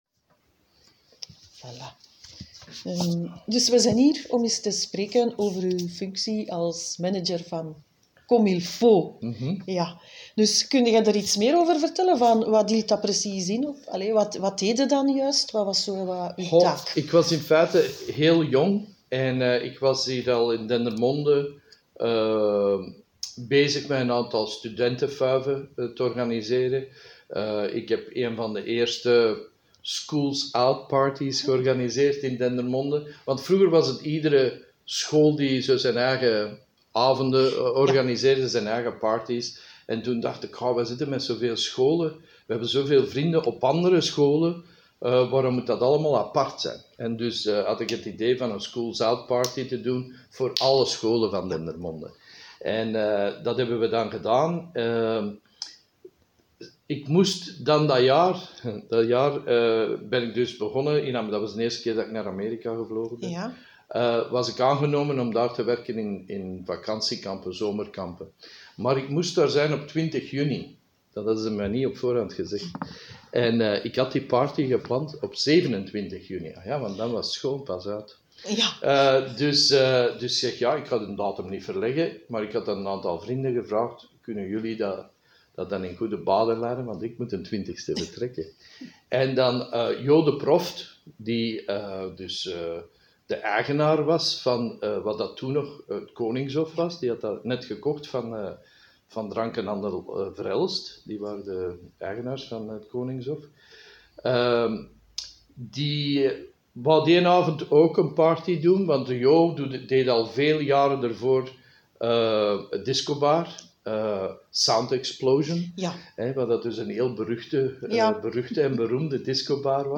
Nachtraven: de interviews